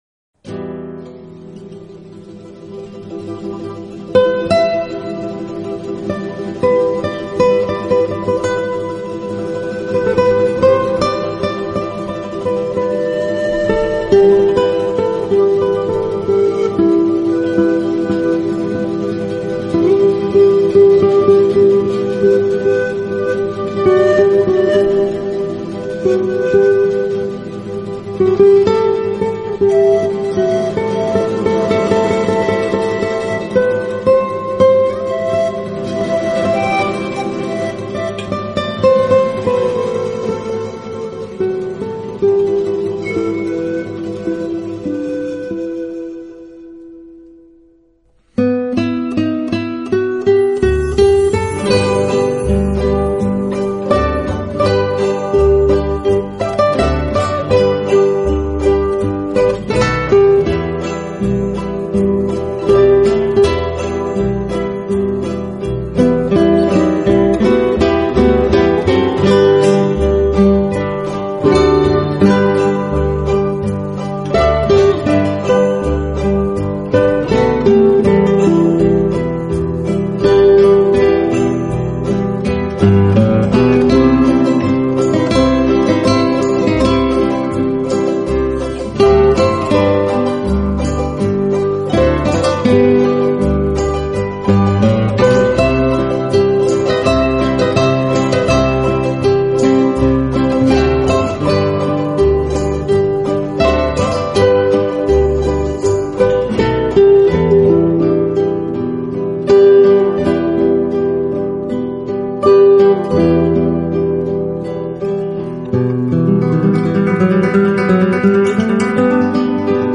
音乐流派： Instrumental
用吉他善于变换的曲调，诠释着音乐人生……